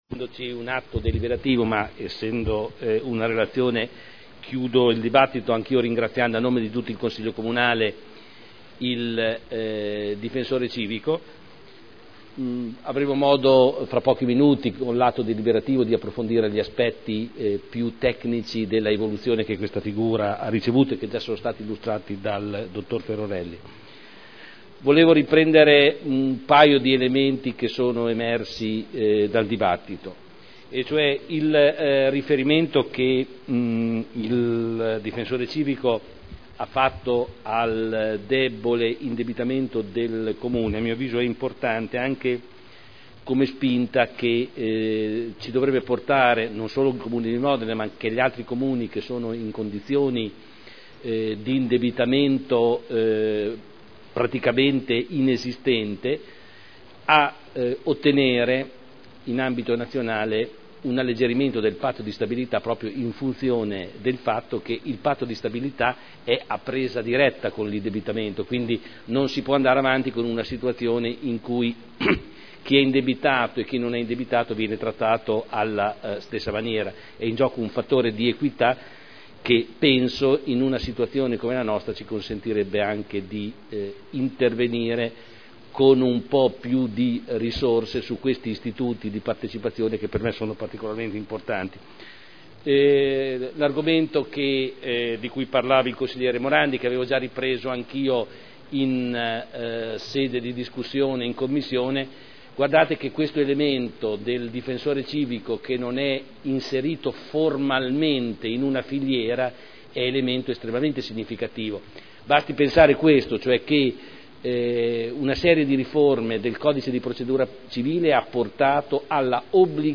Giorgio Pighi — Sito Audio Consiglio Comunale
Seduta del 07/02/2011. Relazione dell'attività svolta dal Difensore Civico del Comune di Modena dal 1° gennaio 2010 al 31 dicembre 2010 dibattito